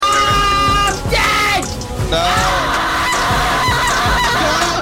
Screaming